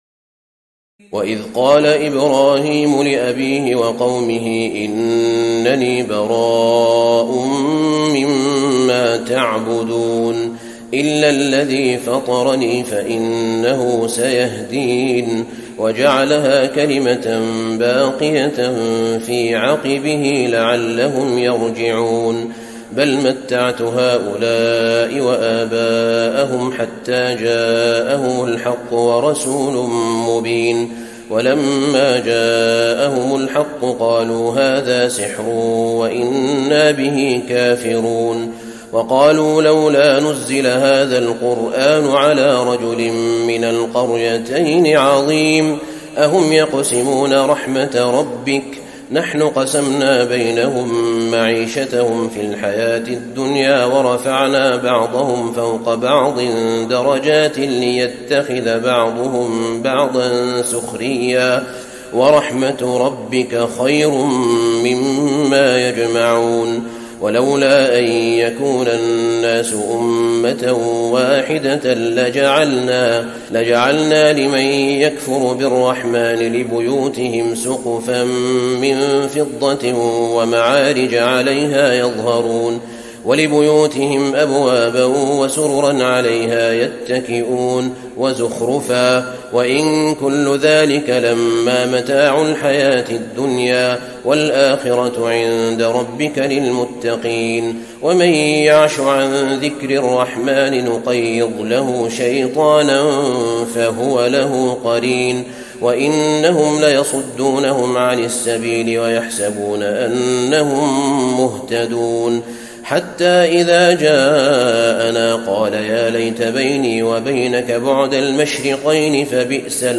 تراويح ليلة 24 رمضان 1435هـ من سور الزخرف (26-89) والدخان و الجاثية Taraweeh 24 st night Ramadan 1435H from Surah Az-Zukhruf and Ad-Dukhaan and Al-Jaathiya > تراويح الحرم النبوي عام 1435 🕌 > التراويح - تلاوات الحرمين